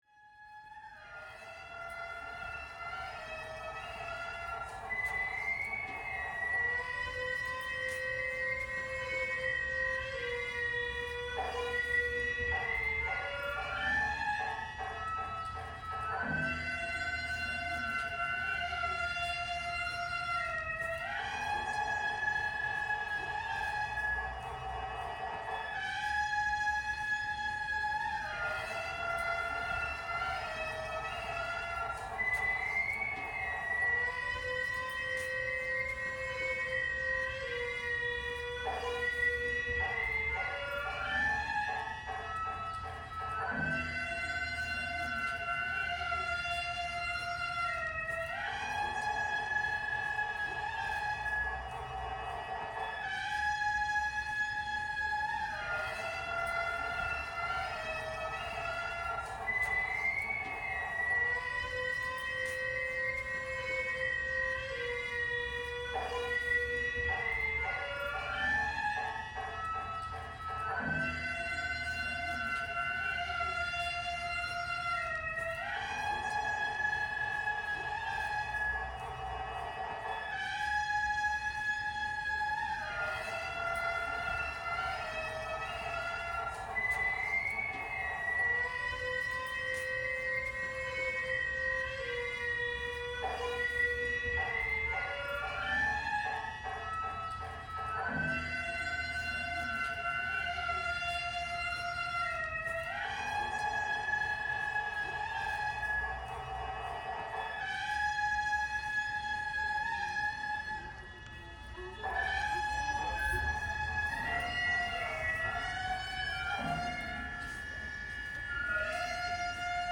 Listen to the sounds of the shrine…